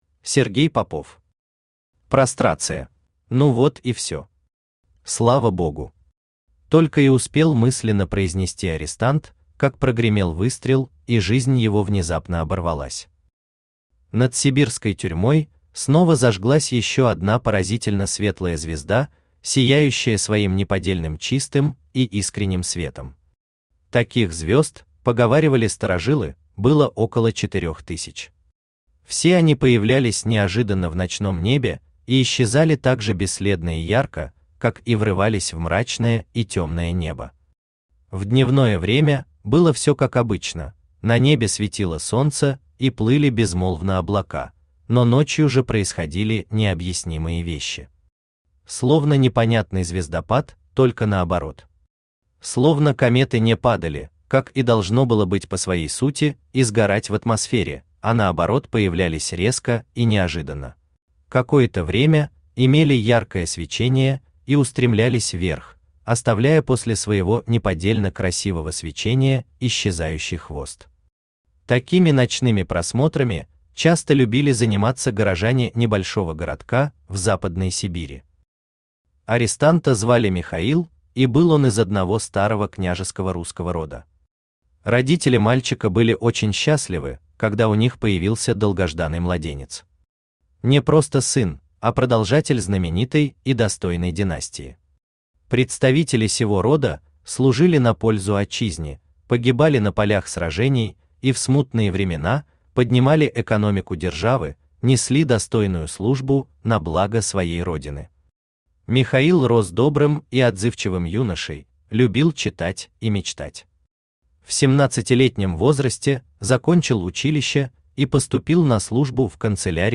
Аудиокнига Прострация | Библиотека аудиокниг